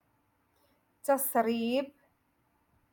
Moroccan Dialect- Rotation Six - Lesson Four